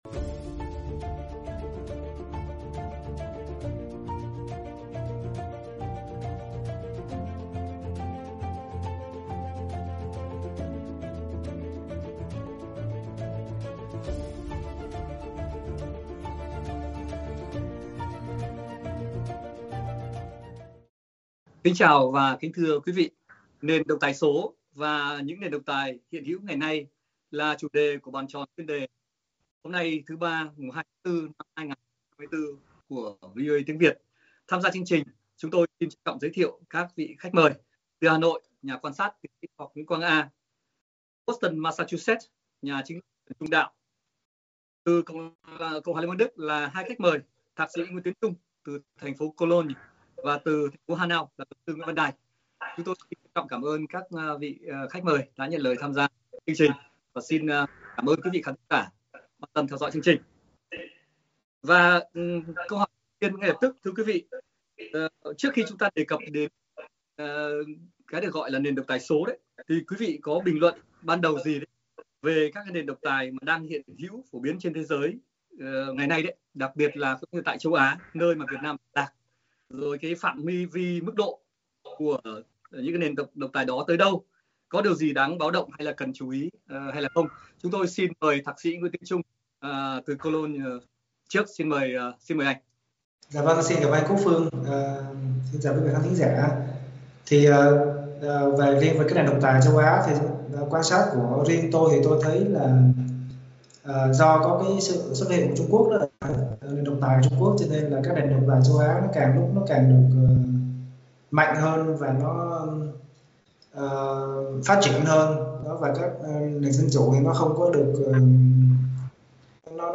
Các khách mời Bàn tròn Chuyên đề thảo luận về những dạng thức, phương thức, thủ đoạn vận hành, sự tồn tại của các nền độc tài chính trên thế giới và tại Á Châu hôm nay, nơi Việt Nam tọa lạc, điểm mạnh yếu và tương lai của chúng.